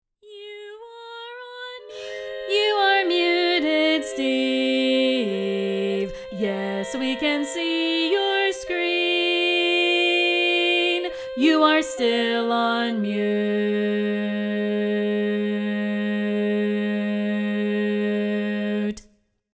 Key written in: C Major
How many parts: 4
Type: Barbershop